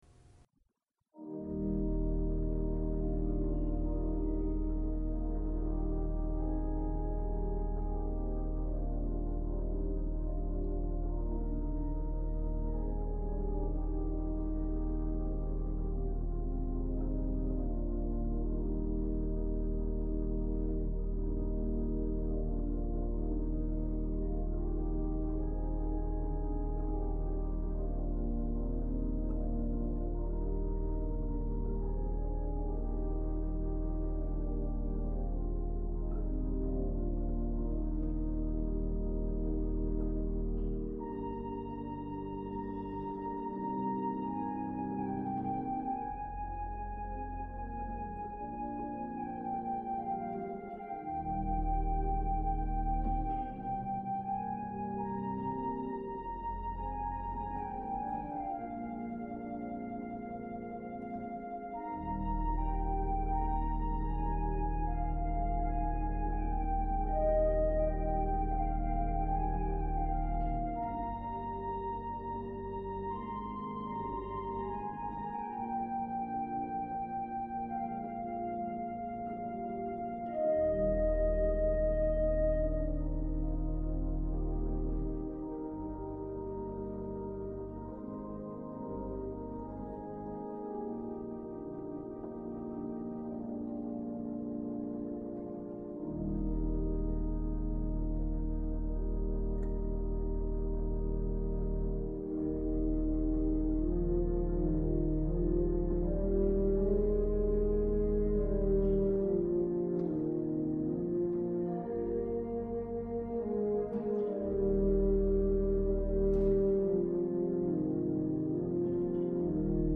These are all amateur recordings but still give a decent impression of each instrument.